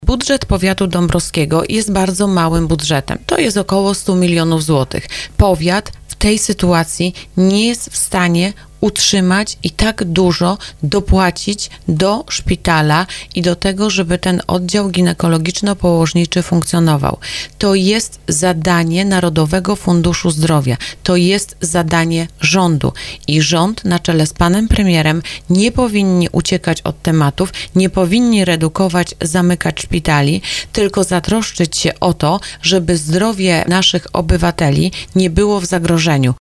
Poseł Prawa i Sprawiedliwości Anna Pieczarka w porannej rozmowie Słowo za Słowo mówiła, że powiat nie jest w stanie sam utrzymać lub dofinansować tego oddziału. Na antenie RDN Małopolska przyznała, że jest to zadanie rządu i Narodowego Funduszu Zdrowia.